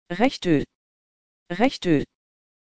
Das klingt doch schon besser, oder?